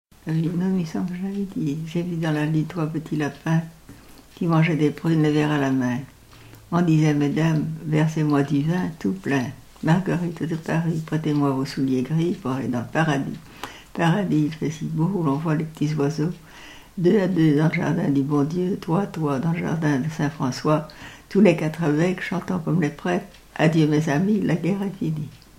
L'enfance - Enfantines - rondes et jeux
Pièce musicale inédite